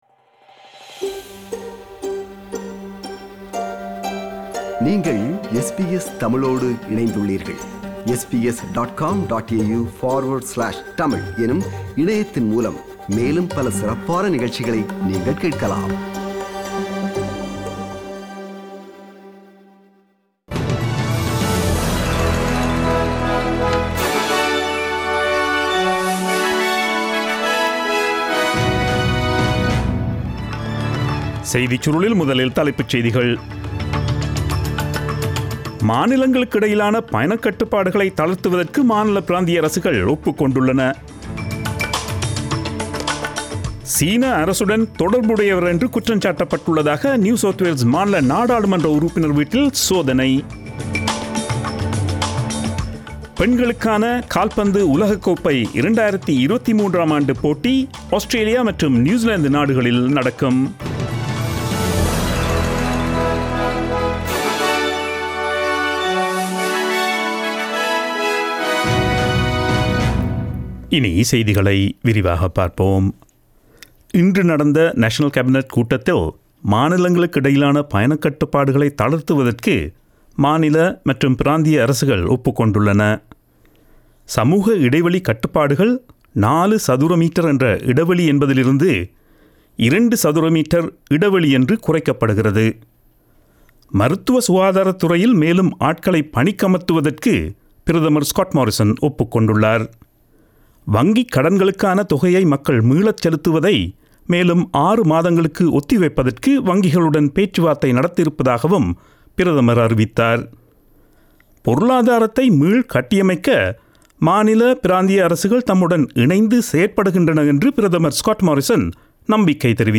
Australian news bulletin aired on Friday 26 June 2020 at 8pm.